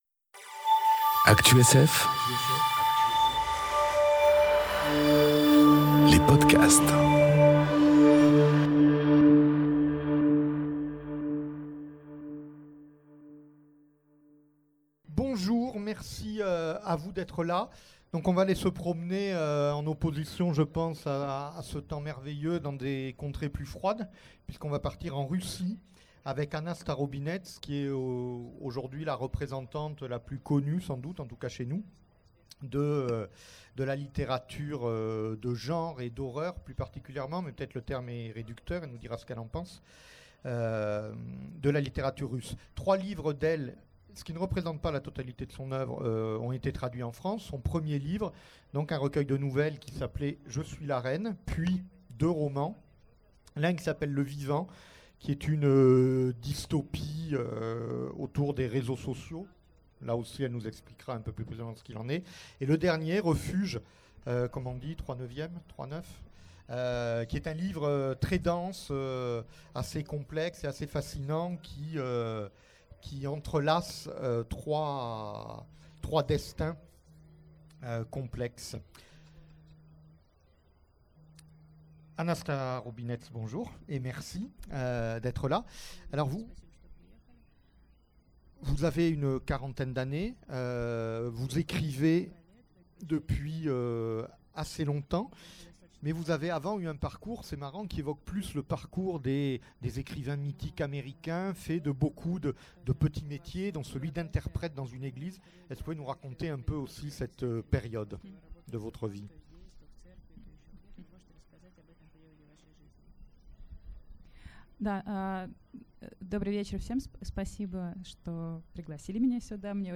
Entretien avec Anna Starobinets enregistré aux Imaginales 2018
Rencontre avec un auteur